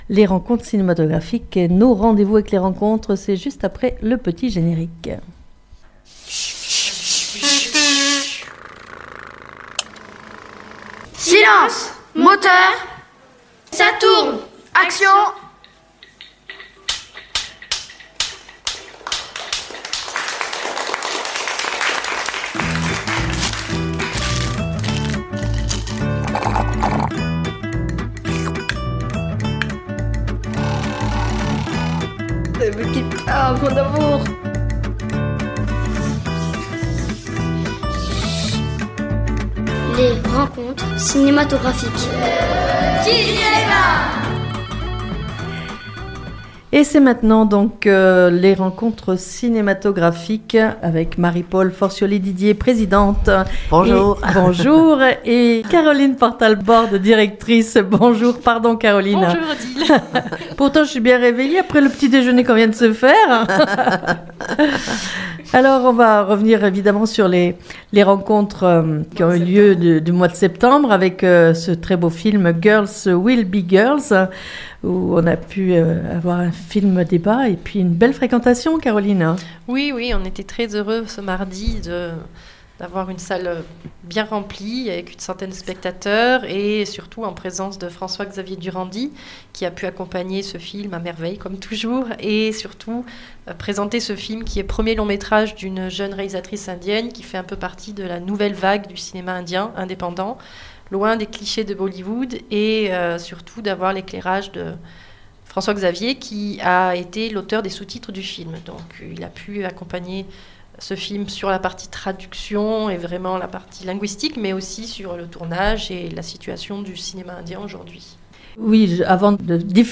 Et nous entendrons quelques bandes annonces des films programmés au mois d'Octobre 2024.